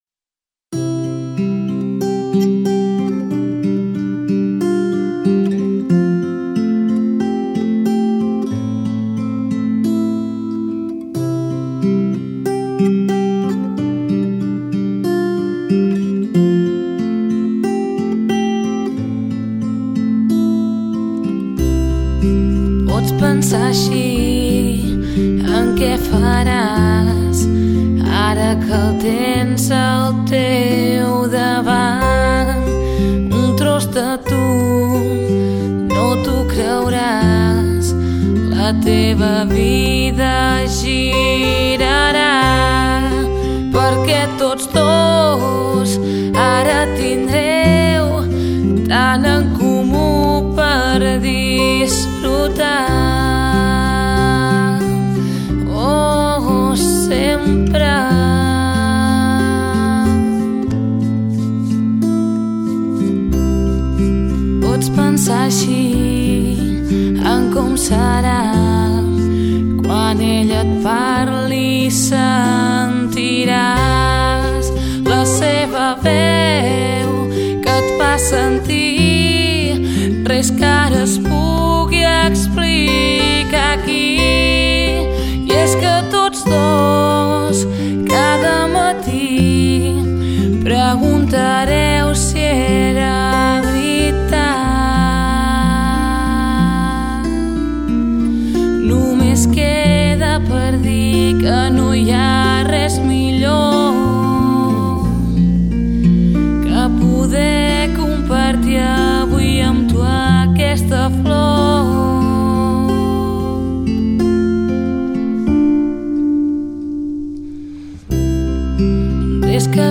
POP
Voz/ Vox
Guitarra/ Guitar
Bajo / Bass
Bateria / Drums
Piano